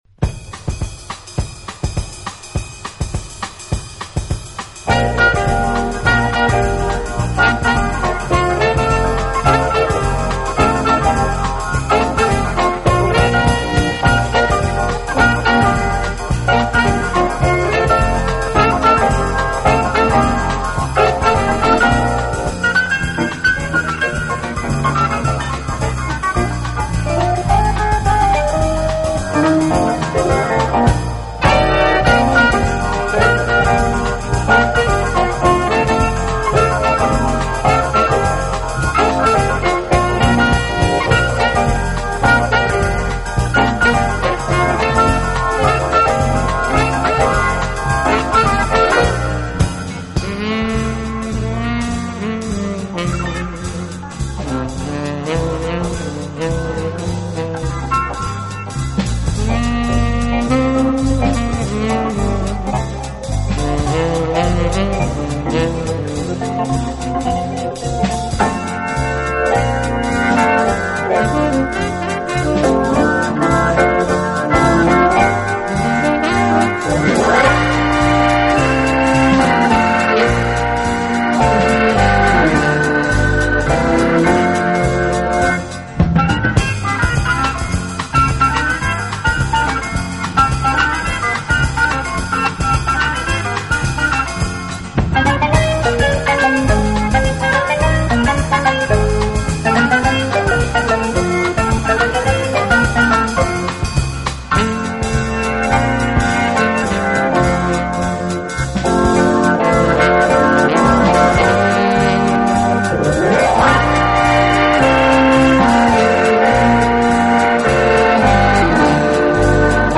引力和动人心弦的感染力。